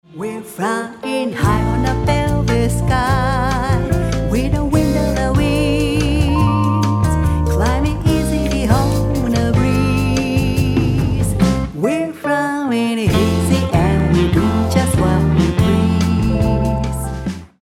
円熟のヴォーカルが描く、ソウル・ジャズ。
Vocal/Chorus
Drums